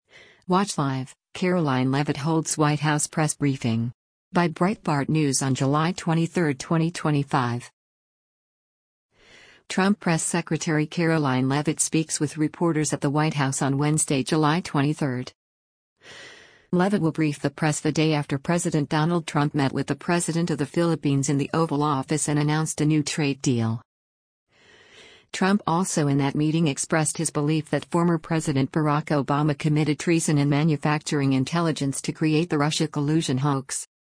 Trump Press Secretary Karoline Leavitt speaks with reporters at the White House on Wednesday, July 23.